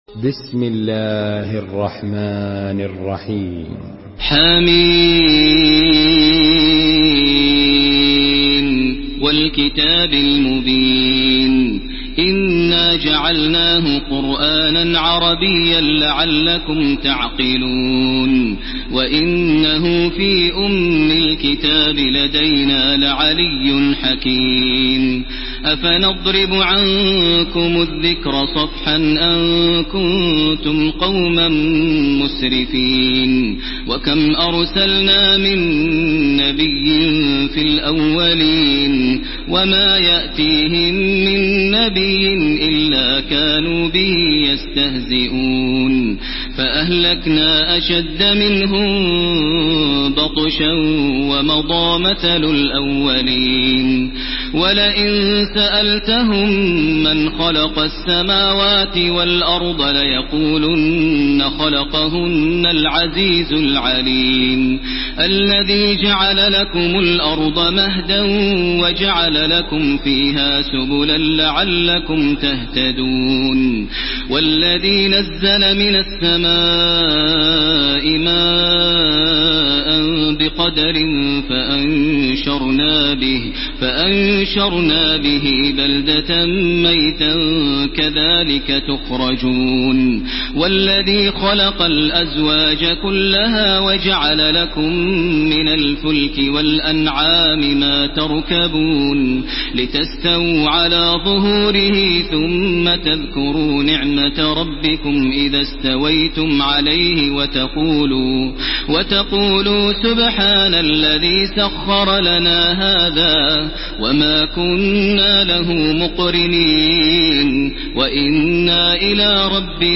Surah Zuhruf MP3 by Makkah Taraweeh 1431 in Hafs An Asim narration.
Murattal